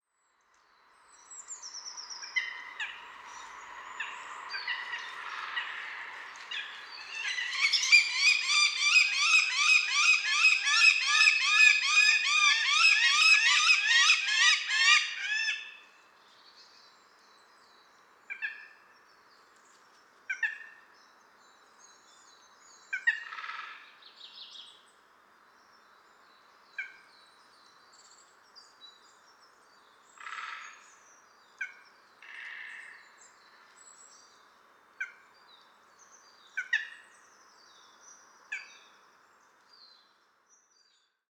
8. Northern Goshawk (Accipiter atricapillus)
Call: Harsh “kak-kak-kak” or scream when threatened.